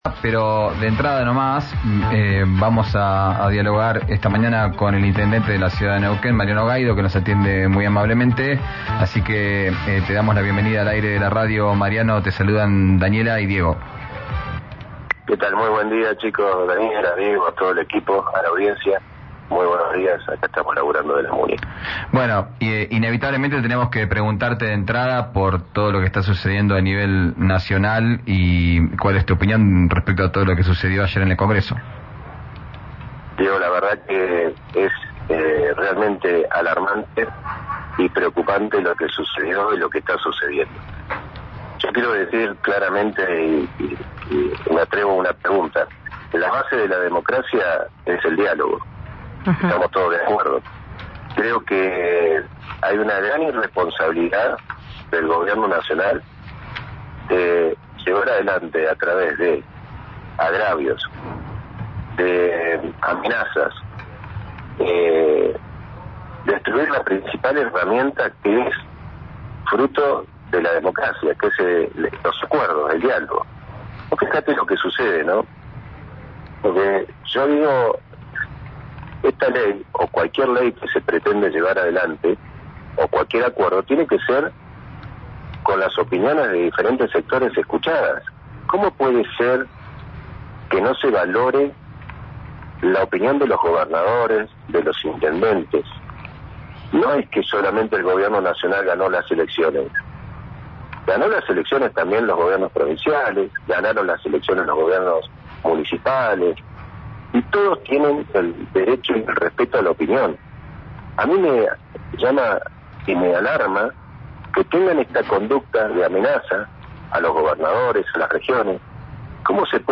Escuchá al intendente de Neuquén, Mariano Gaido, en RÍO NEGRO RADIO: